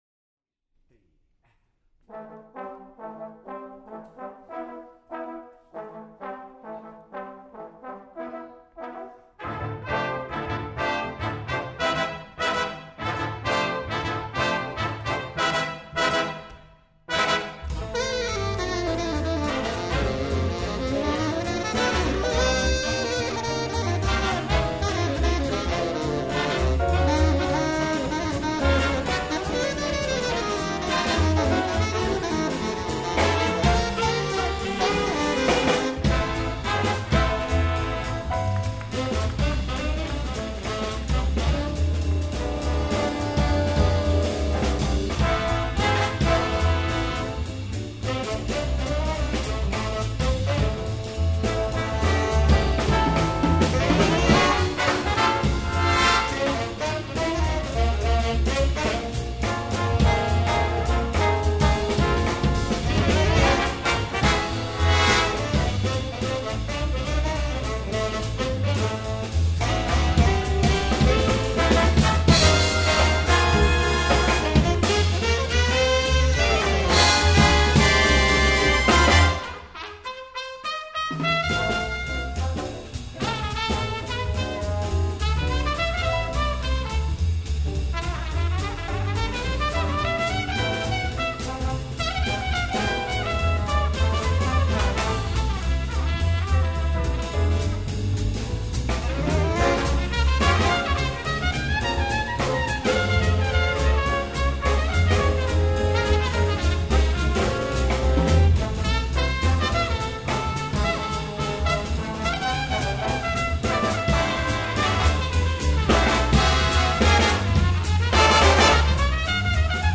tenor saxophone
trumpet soloist.